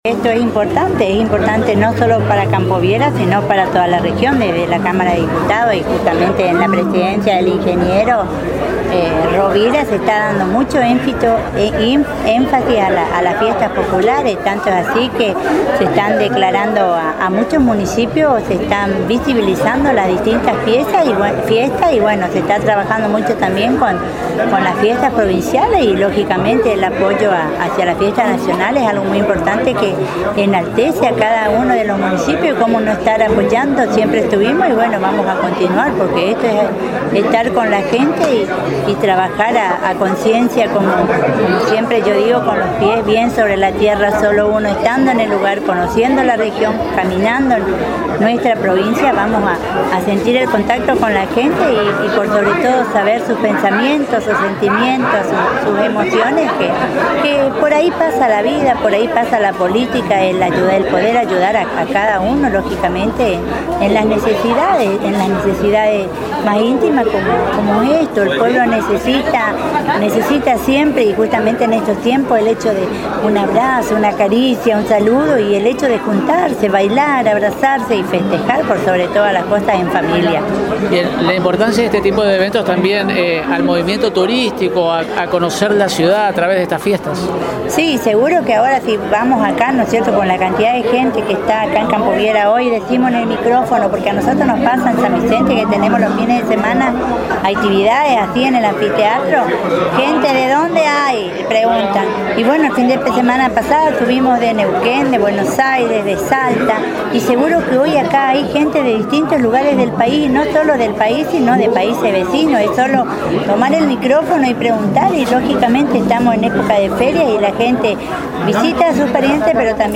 Lanzamiento de la XXXI Fiesta Nacional del Té en Campo Viera - Agencia de Noticias Guacurari
Se realizó el lanzamiento de la XXXI fiesta Nacional del Té en Campo Viera con la presencia del intendente local Juan Carlos Ríos, la Diputada Provincial Cristina Novoa, el programa De Misiones al Mercosur, intendentes de localidades vecinas, la comisión organizadora de dicha fiesta y numeroso público que concurrió a disfrutar de la música misionera.